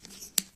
chipsHandle2.ogg